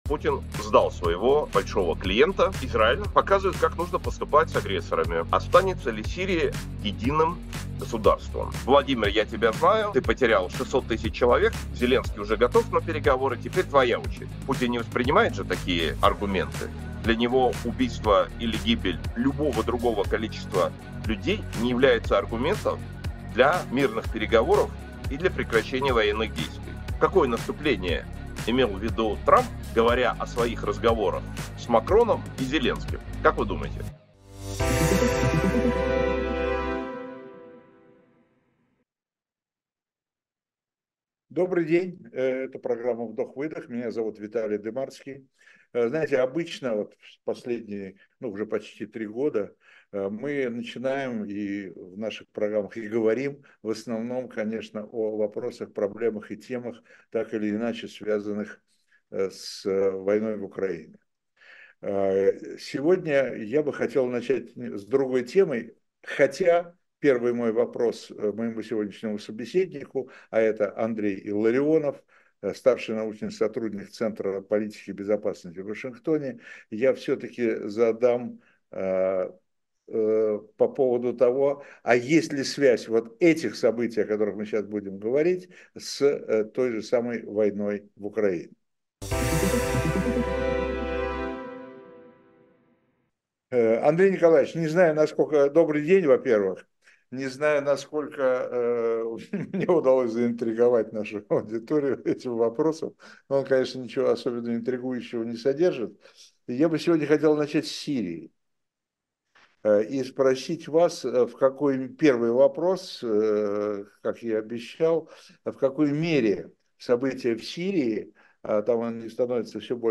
Эфир ведёт Виталий Дымарский
Будущее Сирии 10.12.24 Скачать Срочный сбор для «Эха» Подписаться на «Ходорковский live» Политолог Андрей Илларионов — гость программы «Вдох-выдох» на канале Ходорковский Live. Падение режима Асада в Сирии — как это изменит ситуацию на Ближнем Востоке?